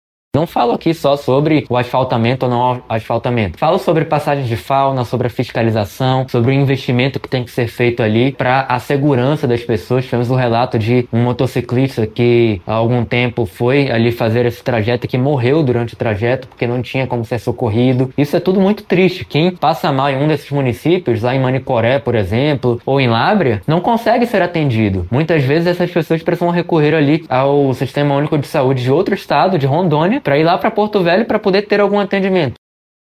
Após percorrer mais de 4.000 quilômetros de Brasília a Manaus, passando pela BR-319, o parlamentar relata que, independentemente do asfaltamento da estrada, é necessário garantir serviços básicos para a população local.